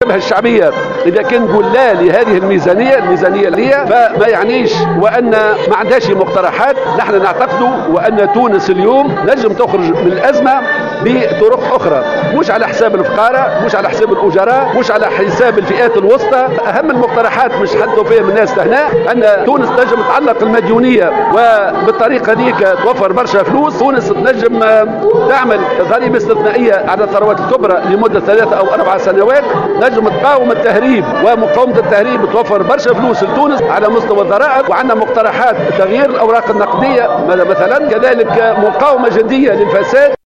وقدم الهمامي مقترحات لتمويل الميزانية بينها تعليق الديون وإقرار ضريبة استثنائية على الثروات الكبرى وتغيير الأوراق النقدية ومقاومة الفساد والتهريب بجدية، بحسب تعبيره. وجاءت تصريحاته على هامش اجتماع شعبي نظمته الجبهة الشعبية عشية اليوم السبت بمنطقة سيدي حسين السيجومي، بخصوص قانون المالية لسنة 2017.